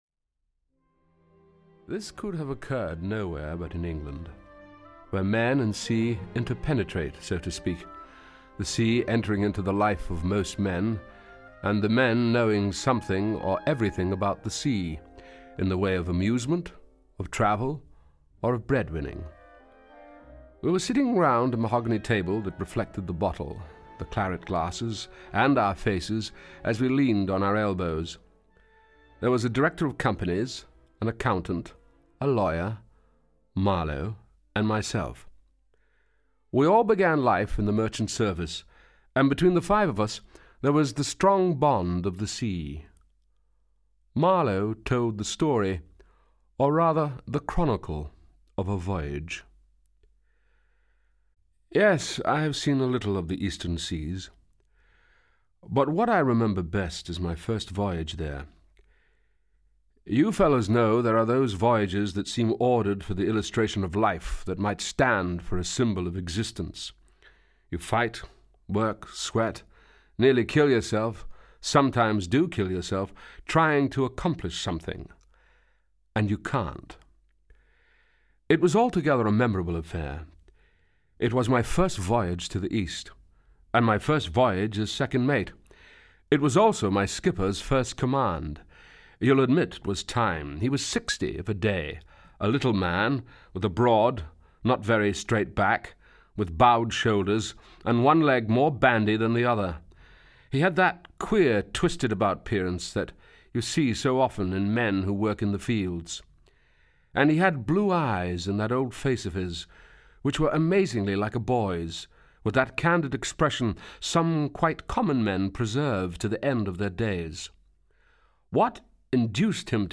Audio kniha
• InterpretBrian Cox